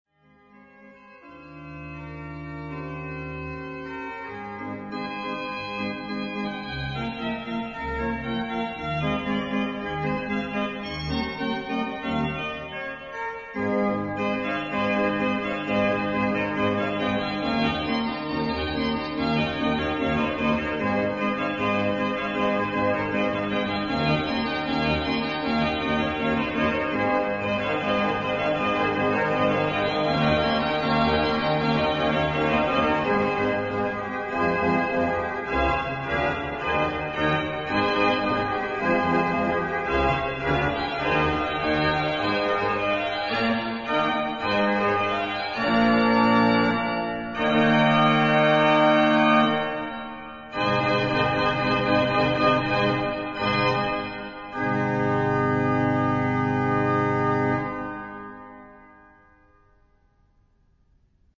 音響はオルガンの美しいパイプと石造りの建築が産みだすのだ、という事を痛感する。
場所：聖ボニファシウス教会（オランダ、メデンブリック）